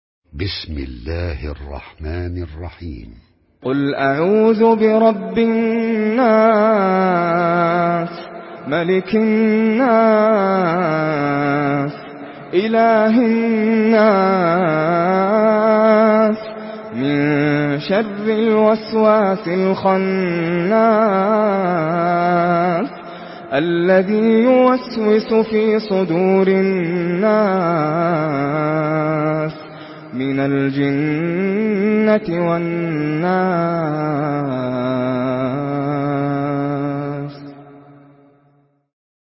Surah আন-নাস MP3 by Nasser Al Qatami in Hafs An Asim narration.